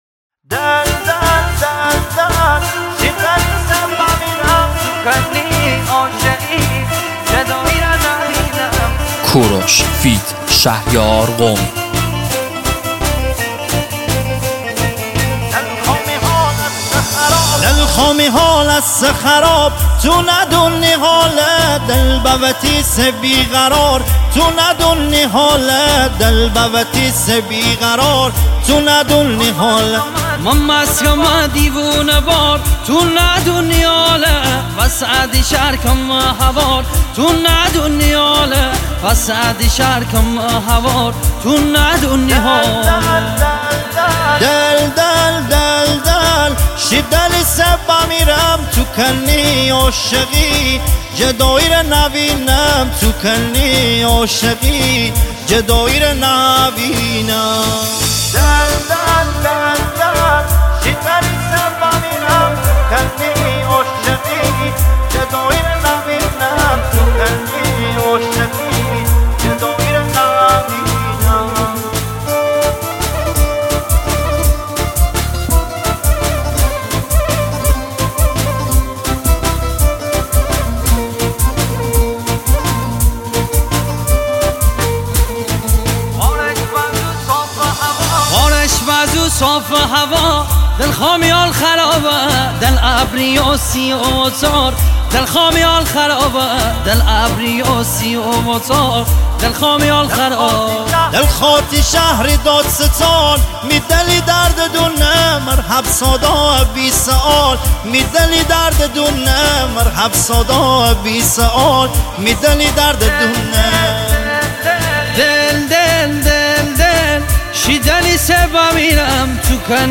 آهنگ شمالی